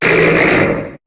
Cri de Téraclope dans Pokémon HOME.